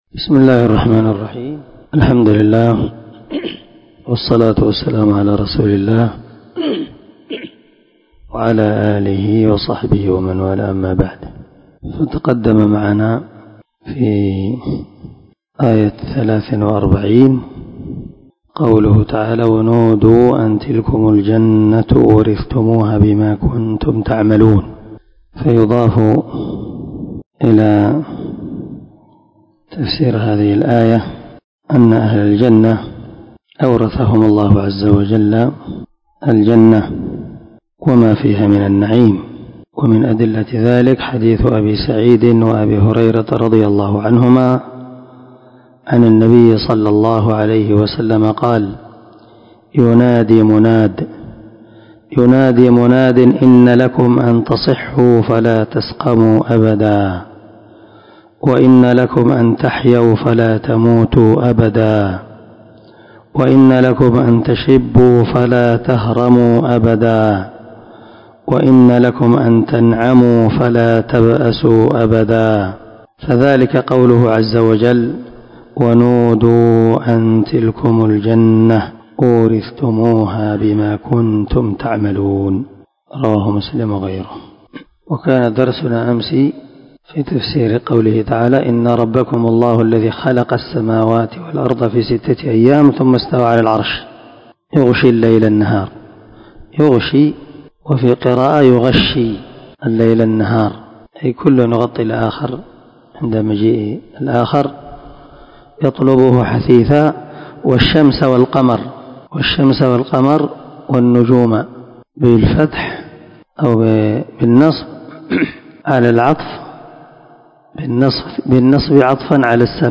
467الدرس 19 تفسير آية ( 55 – 56 ) من سورة الأعراف من تفسير القران الكريم مع قراءة لتفسير السعدي